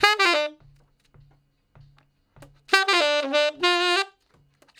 066 Ten Sax Straight (D) 01.wav